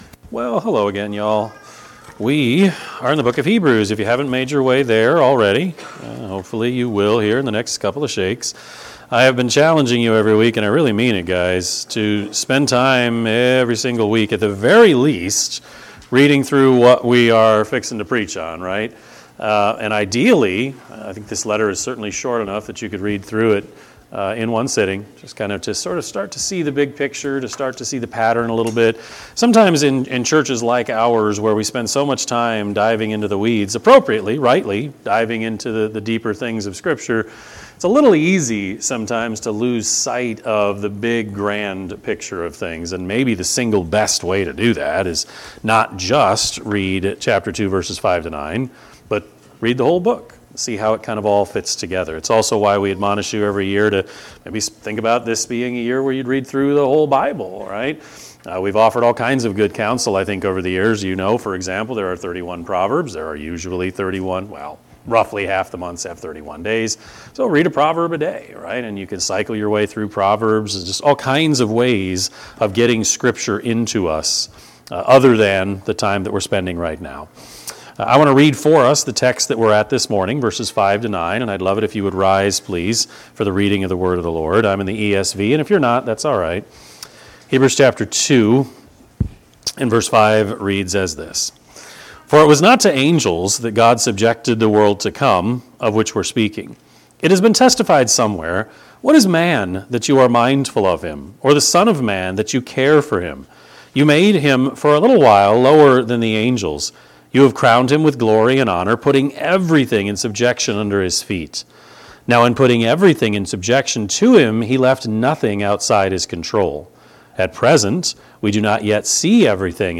Sermon-6-15-25.mp3